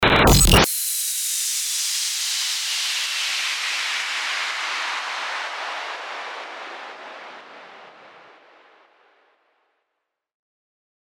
FX-1612-STARTER-WHOOSH
FX-1612-STARTER-WHOOSH.mp3